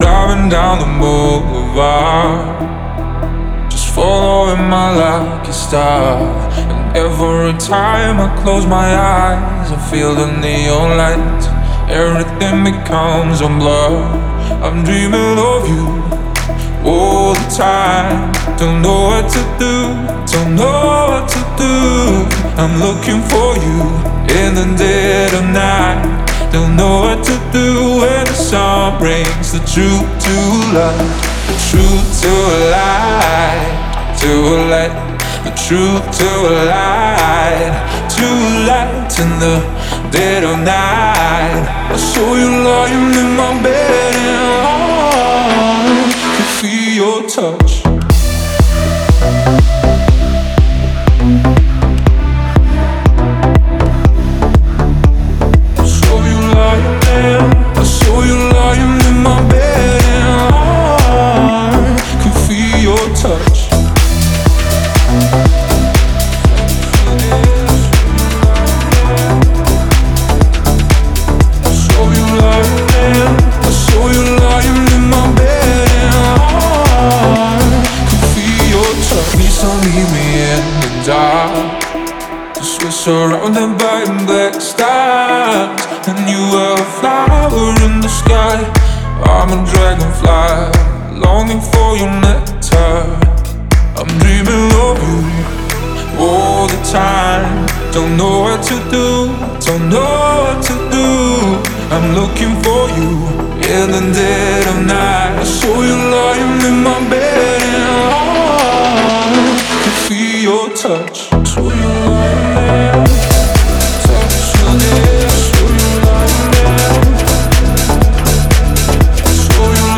это зажигательная композиция в жанре EDM